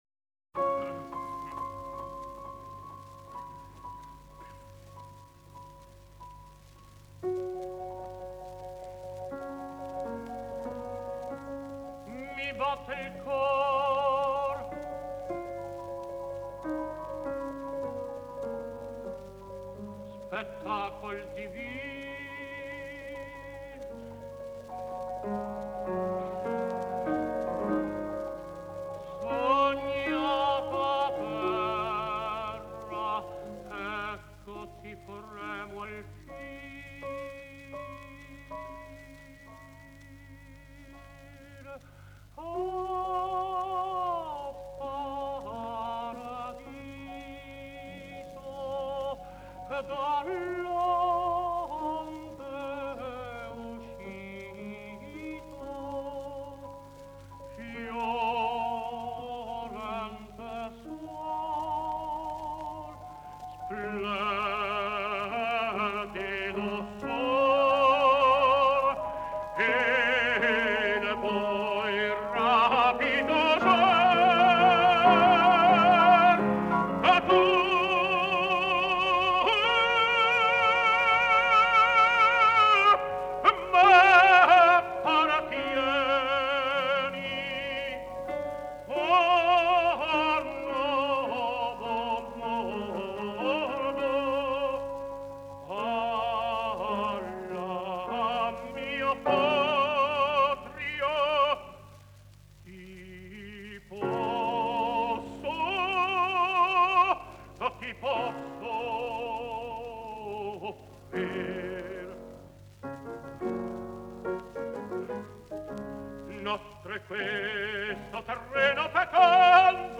piano
Live in Stockholm